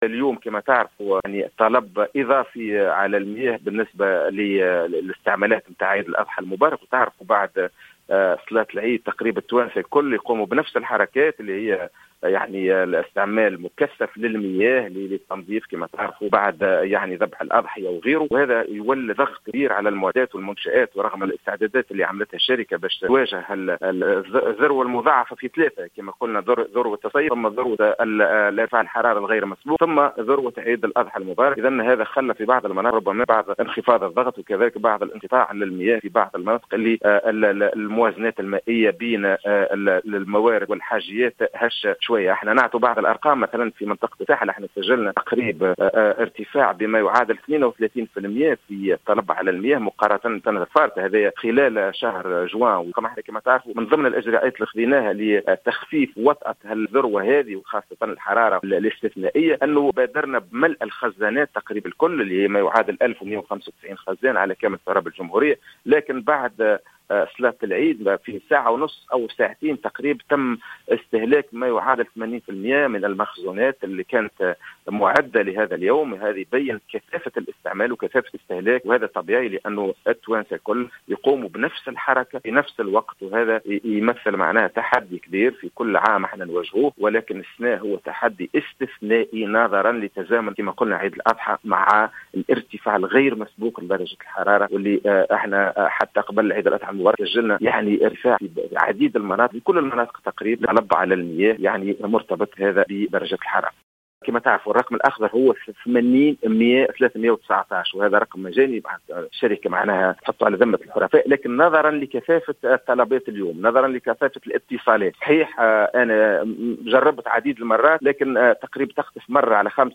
وأبرز الهلالي، في تصريح للجوهرة أف أم، أن ارتفاع الطلب الإضافي على المياه في منطقة الساحل على سبيل الذكر، بلغ 32% خلال شهري جوان وجويلية، تزامنا مع أوج الموسم السياحي.